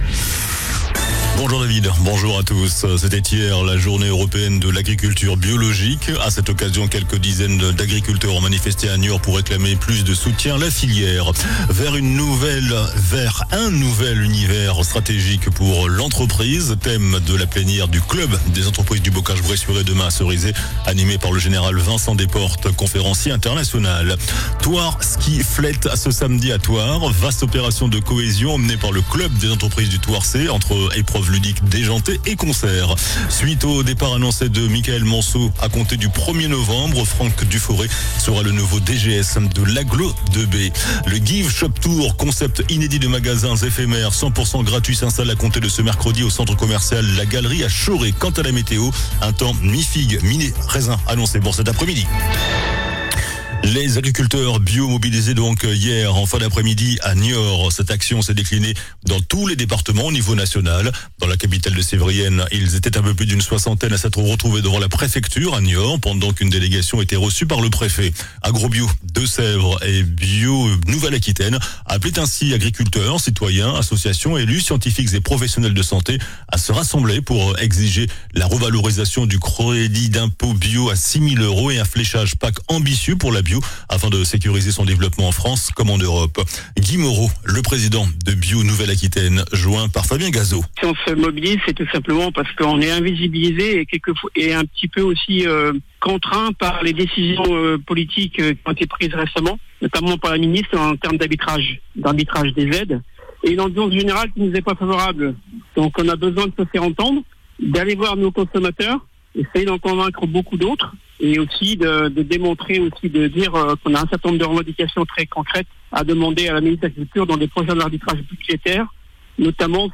JOURNAL DU MERCREDI 24 SEPTEMBRE ( MIDI )